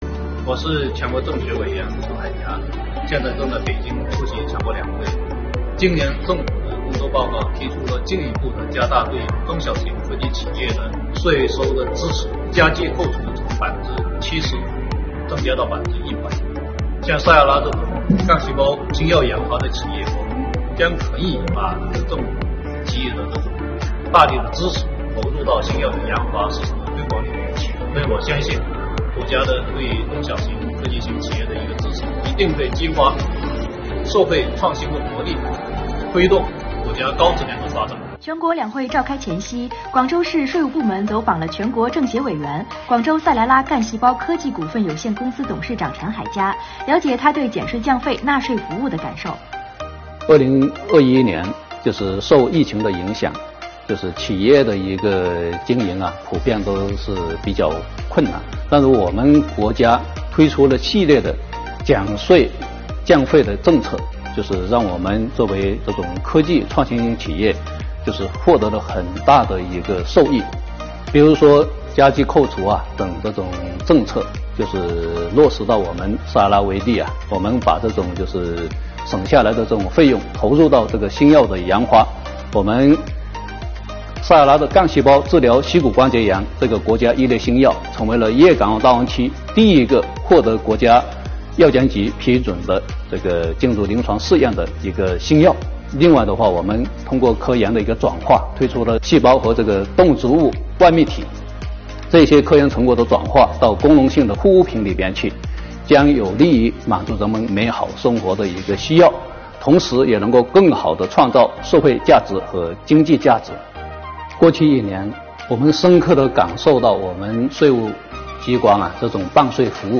全国两会召开前夕，国家税务总局广州市黄埔区（广州开发区）税务局税务干部走访了陈海佳，了解他对减税降费、纳税服务的感受及建议。